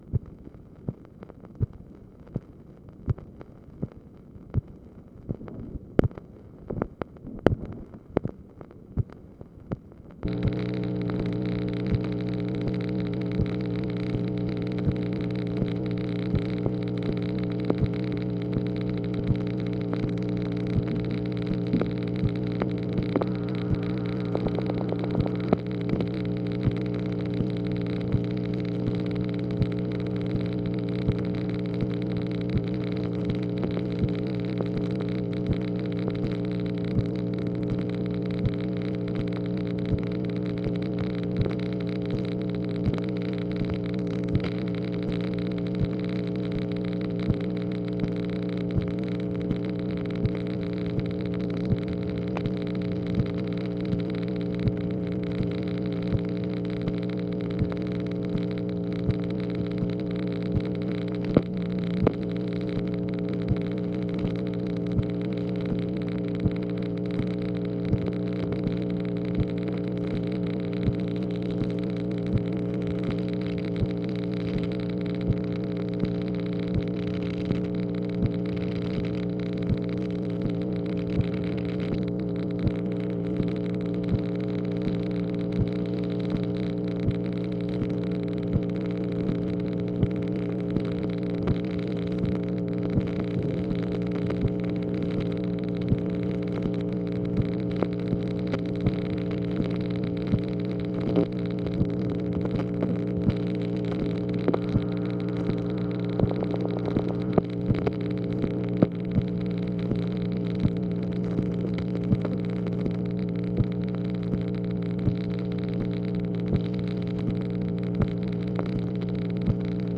MACHINE NOISE, March 23, 1965
Secret White House Tapes | Lyndon B. Johnson Presidency